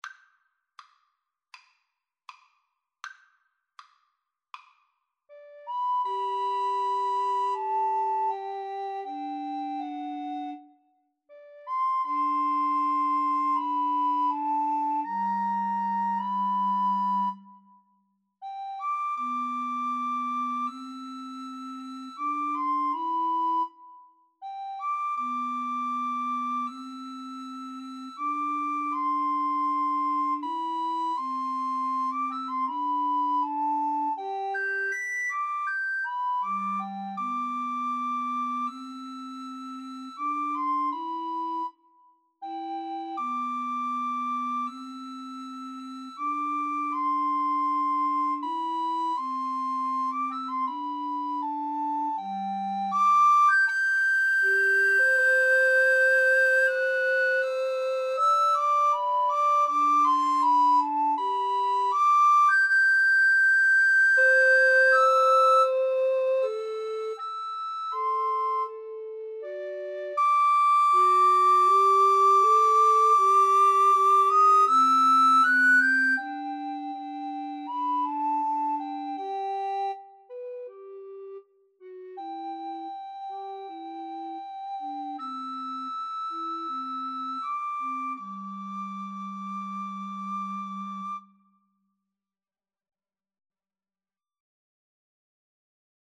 G major (Sounding Pitch) (View more G major Music for Recorder Trio )
Andante